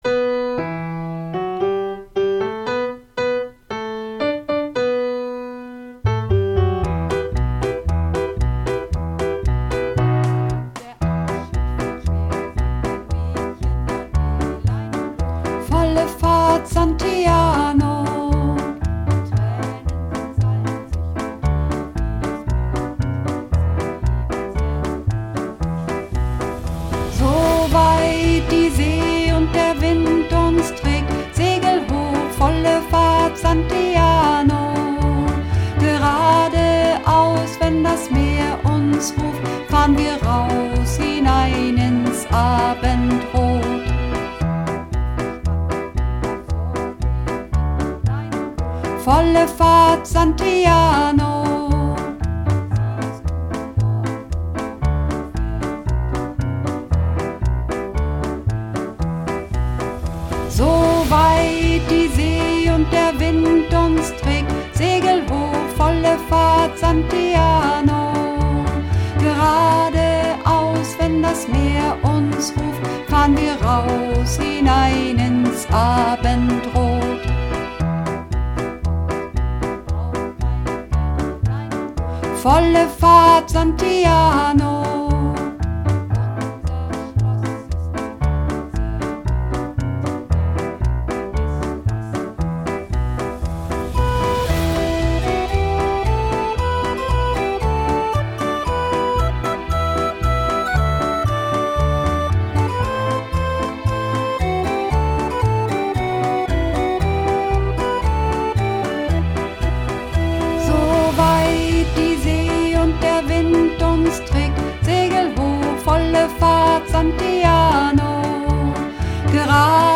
Übungsaufnahmen - Santiano
Santiano (Alt)